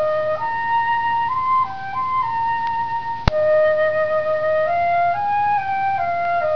Take the audio file flute.wav, and modify it so that the second half is played before the first half.
flutesliced.wav